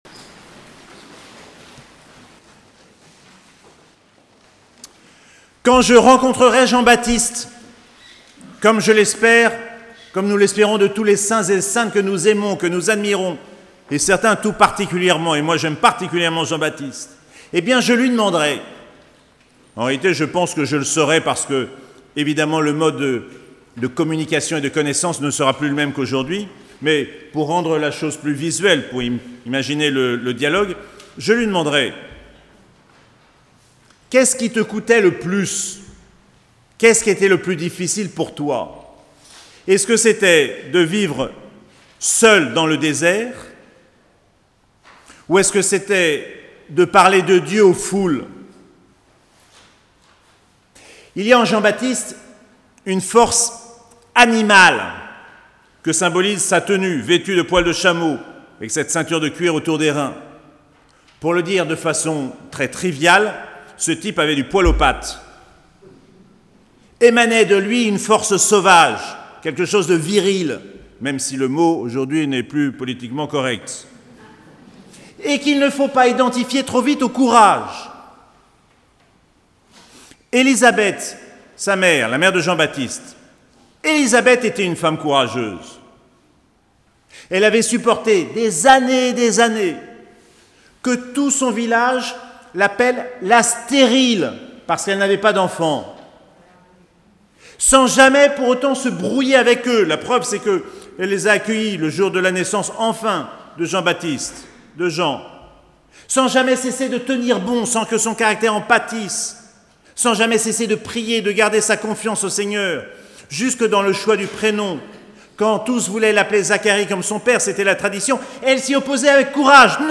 Précédentes homélies